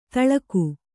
♪ taḷaku